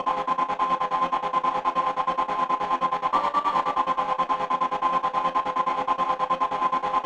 synth.wav